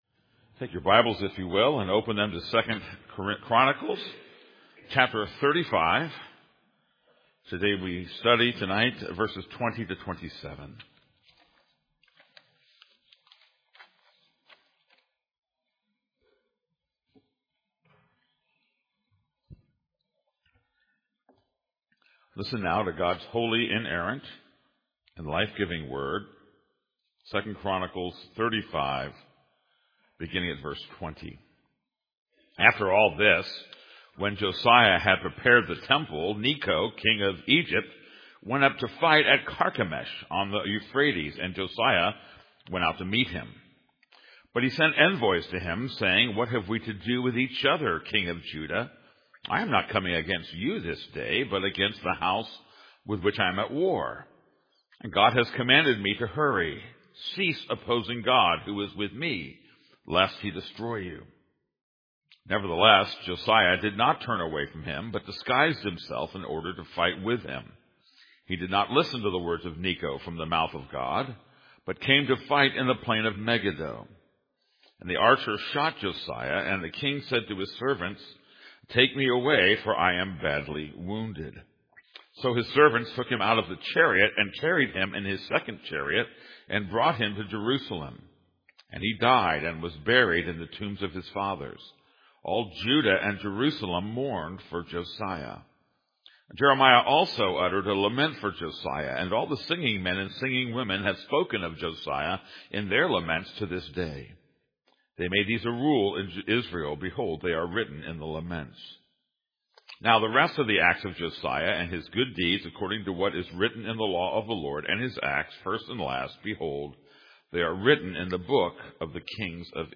This is a sermon on 2 Chronicles 35:20-27.